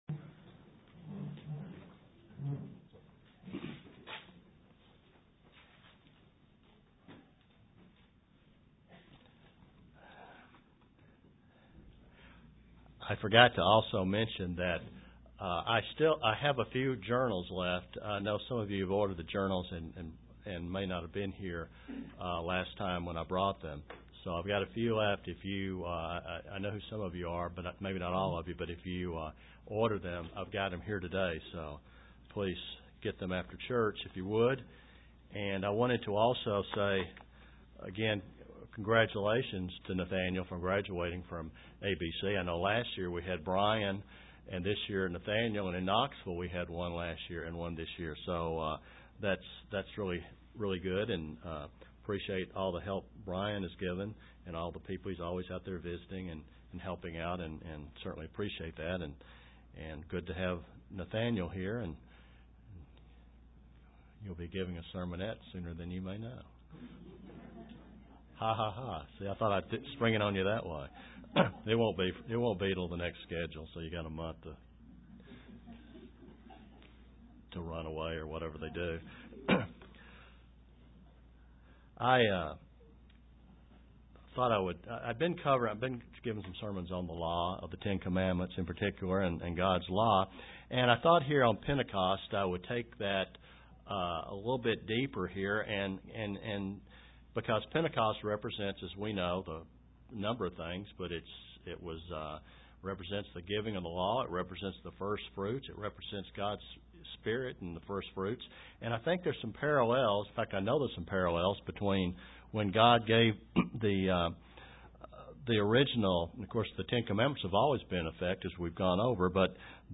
Given in Kingsport, TN
UCG Sermon Studying the bible?